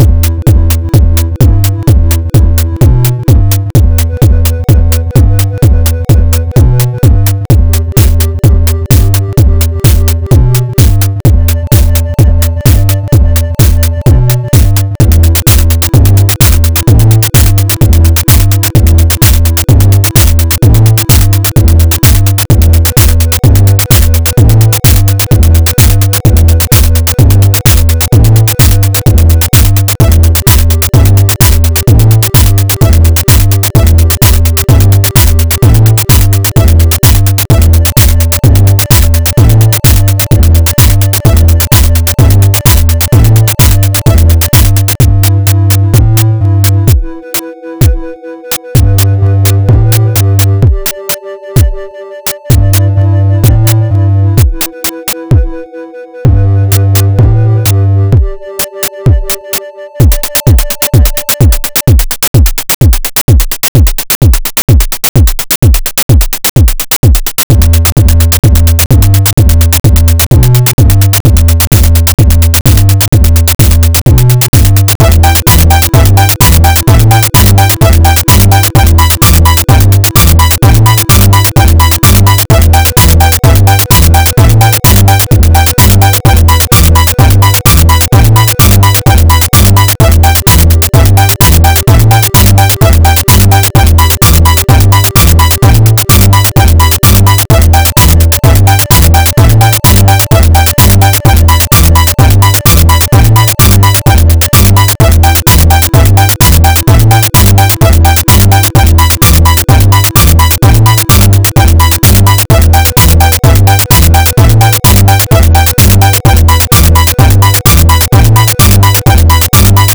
Nintendo Infinite Techno
• 12 bass types: Deep, Acid, Wobble, Gritty, PVC, FM, Hollow, Punchy, Resonant, Sub, Pluck